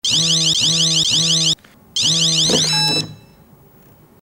Tono de teléfono móvil que vibra
teléfono móvil
vibración
Sonidos: Comunicaciones